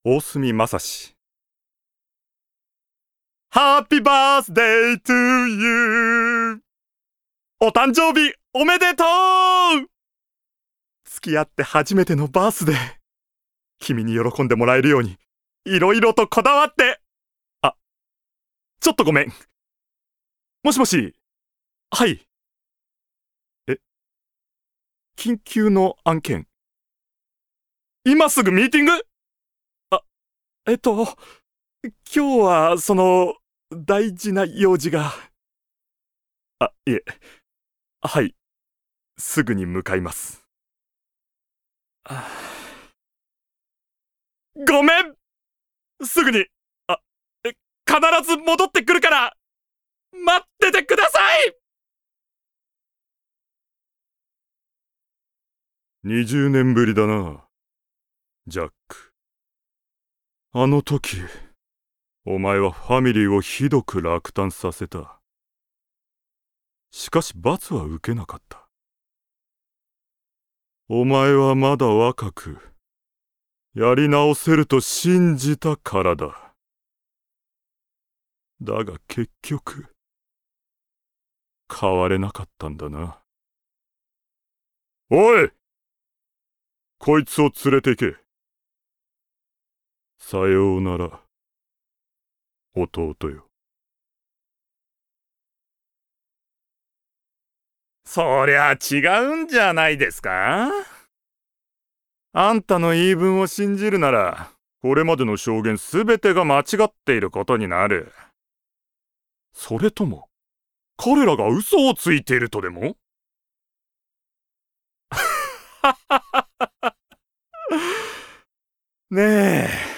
誕生日： 7月21日 血液型： A型 身 長： 178cm 出身地： 愛知県 趣味・特技： 散歩・イラスト・カラオケ・3DCG制作・剣道・ヨット 資格： 普通自動車運転免許 音域： F#〜 D 方言： 三河弁
VOICE SAMPLE